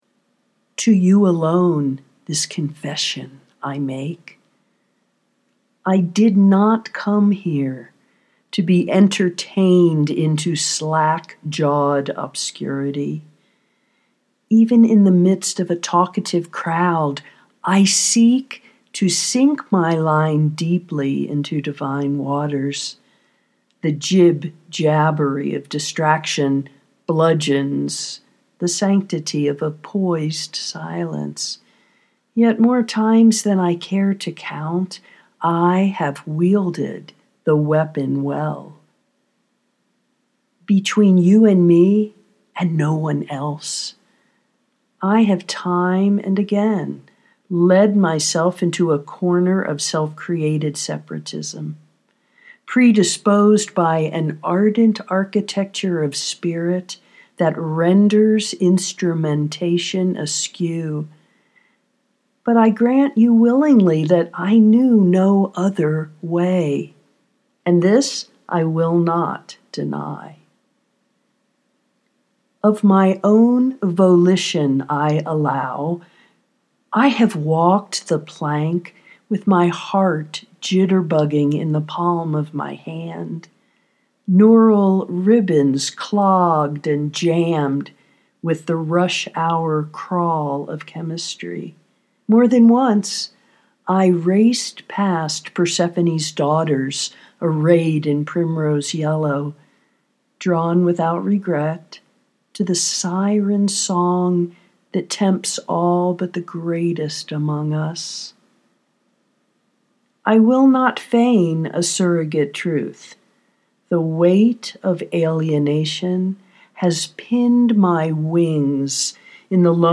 birthing bed confession (audio poetry 2:30)